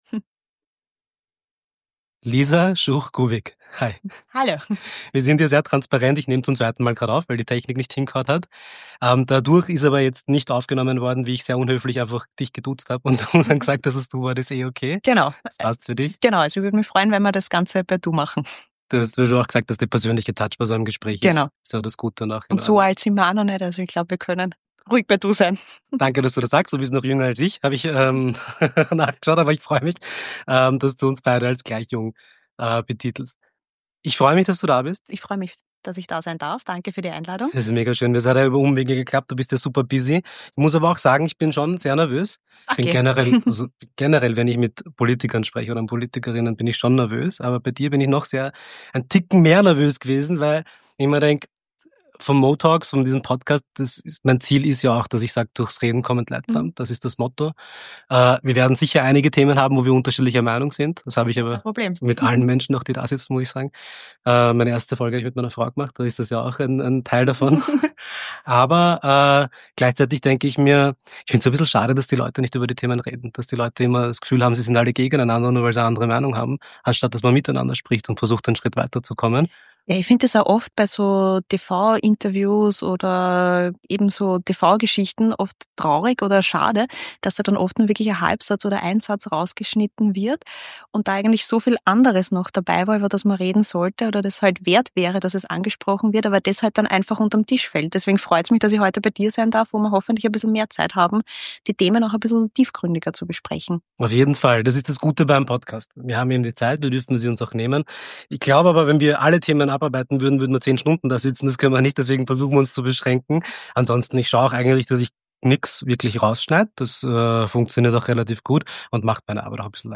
Er hat sich mit Lisa Schuch-Gubik, Nationalratsabgeordnete und FPÖ-Bundessprecherin, getroffen. In einem ehrlichen Gespräch erklärt sie, warum sie sich gegen die Unterstellungen des Rassismus wehrt, warum wichtige Themen ihrer Meinung nach im Parlament gar nicht besprochen werden, wie sie zu Integration steht und was sie sich für die politische Kultur in Österreich wünscht.